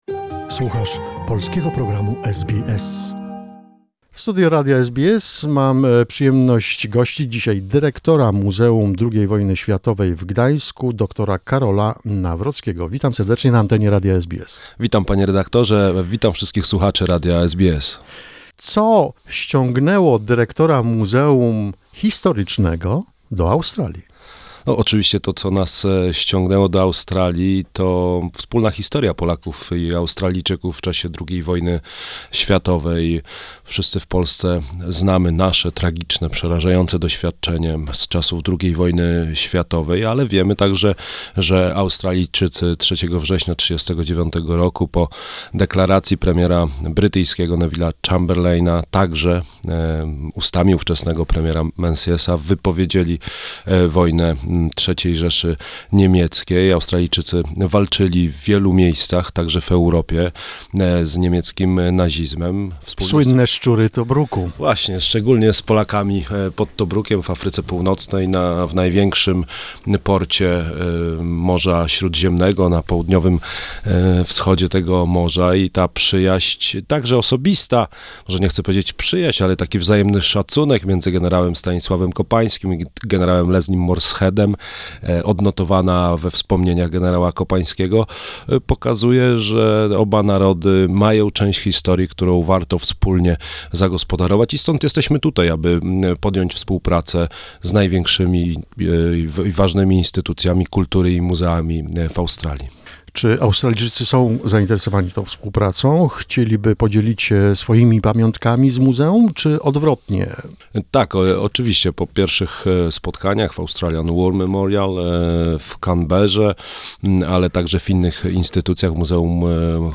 Interview with the director of the Museum of the World War II in Gdańsk, Dr. Karol Nawrocki, who, together with a museum delegation, visits Australia and meets representatives of Australian museums of the WW II, as well as records interviews with Polish veterans living in Australia.
Dr. Karol Nawrocki, dyrektor Muzeum II Wojny Światowej w Gdańsku w studio Radia SBS.